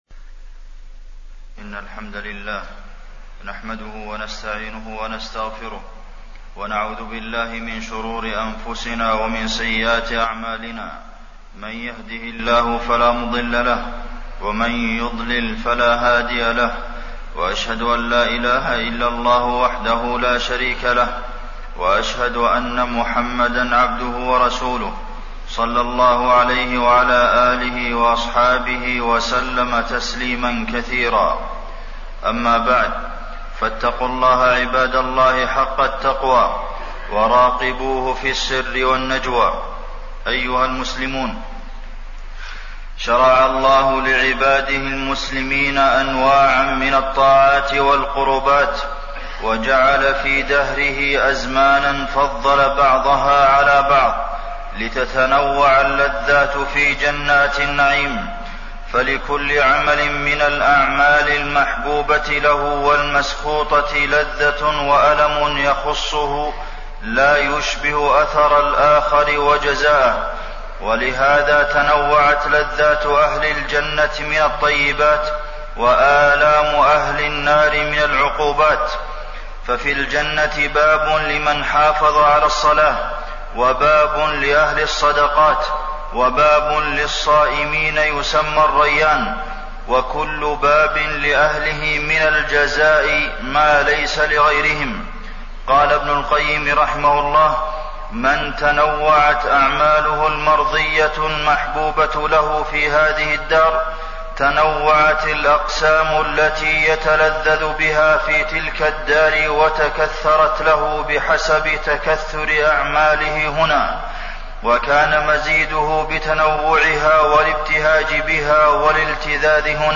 تاريخ النشر ١٤ رمضان ١٤٣٠ هـ المكان: المسجد النبوي الشيخ: فضيلة الشيخ د. عبدالمحسن بن محمد القاسم فضيلة الشيخ د. عبدالمحسن بن محمد القاسم رمضان